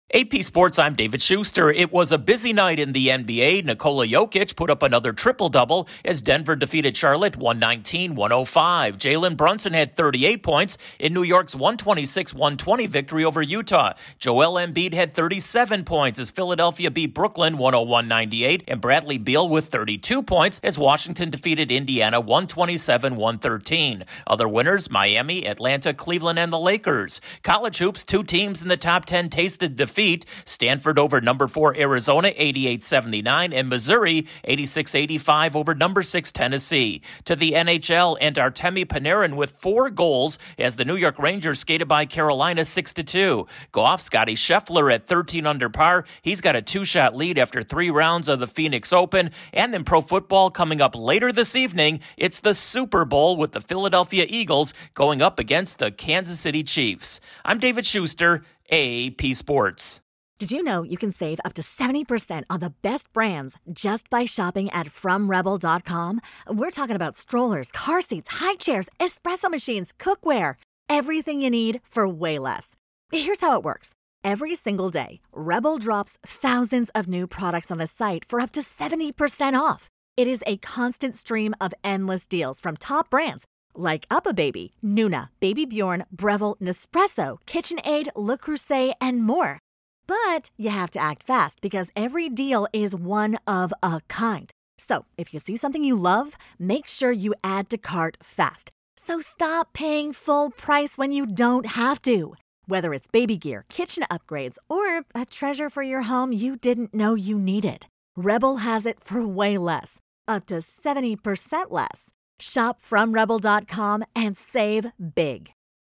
The Nuggets, Knicks, 76ers and Wizards are among the NBA winners, Arizona and Tennessee fall in college hoops, a four-goal game in the NHL, Scottie Scheffler leads through 54 holes and the Super Bowl is hours away. Correspondent